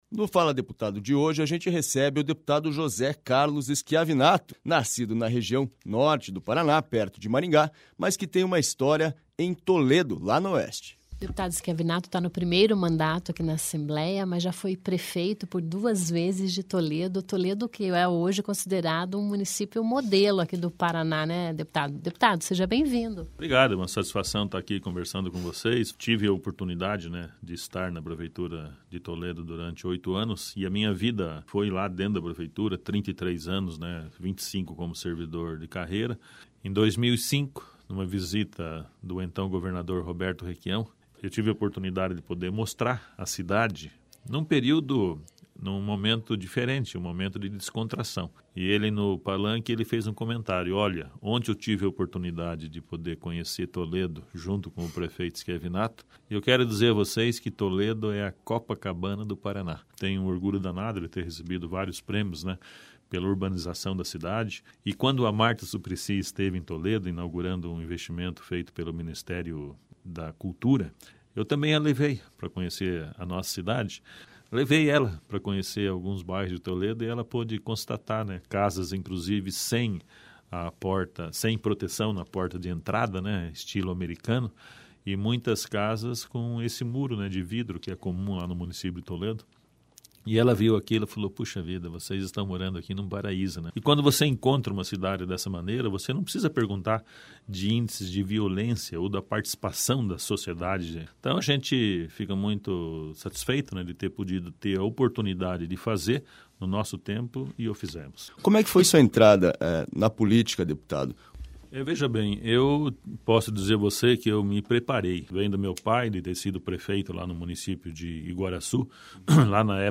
O premiado José Carlos Schiavinato está nas ondas da Rádio Assembleia desta semana. Ele é o entrevistado do programa “Fala Deputado” De serviço público ele entende bem, afinal, foram 25 anos atuando como servidor de carreira e outros oito como prefeito do município de Toledo, no Oeste do Paraná.